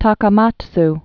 (täkä-mäts)